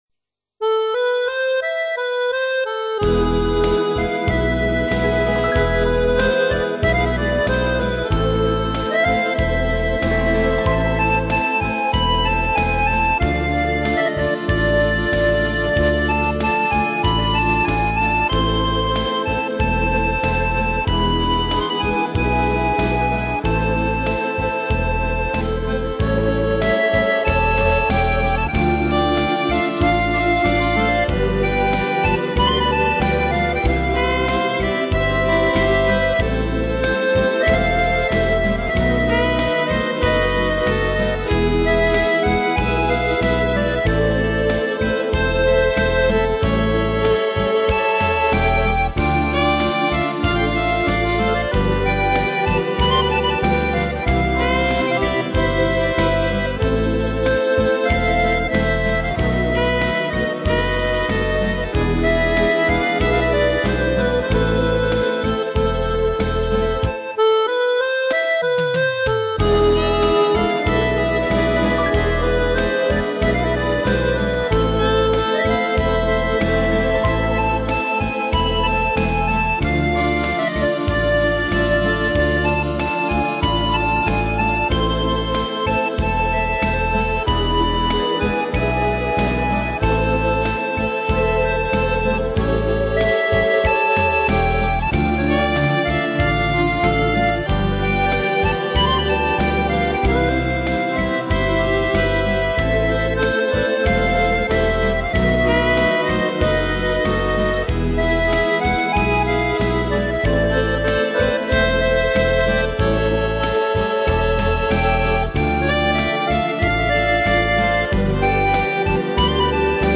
Sang og musikk